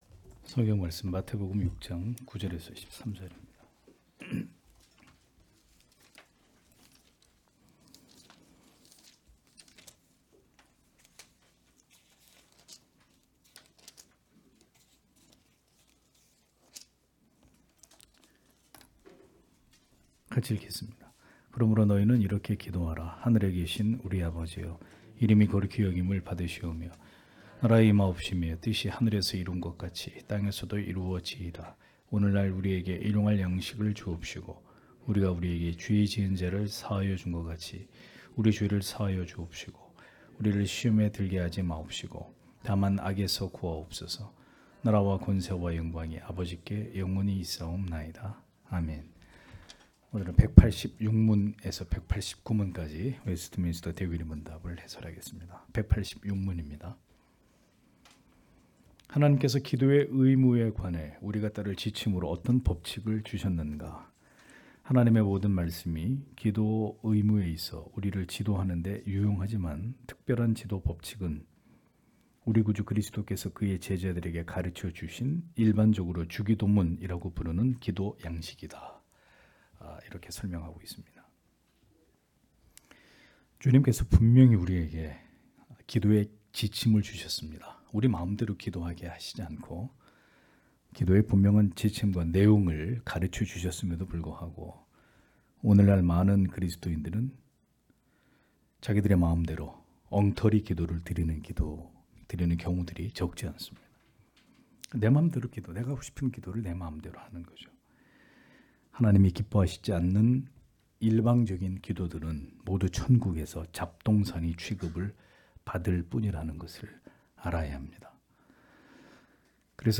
주일오후예배 - [웨스트민스터 대요리문답 해설 186-189] 186문-189문) (마태복음 6장 9-13절)